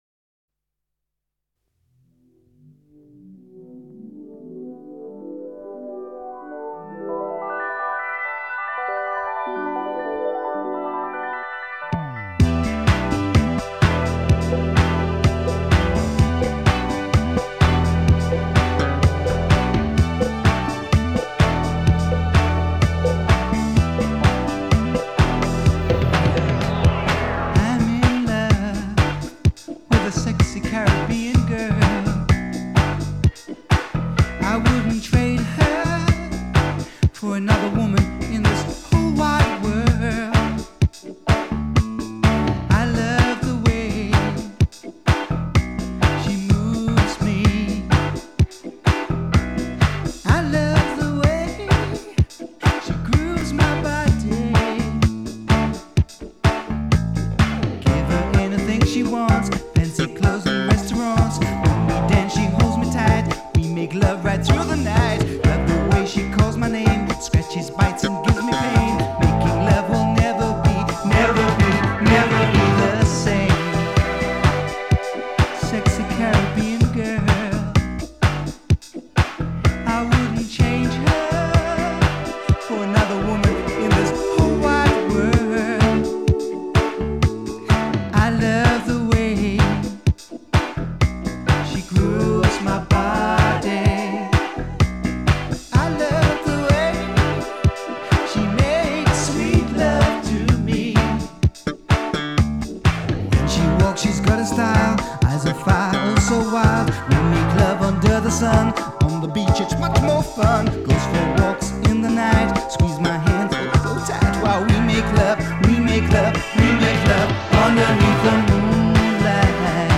стили фанк и соул с элементами поп-рока и регги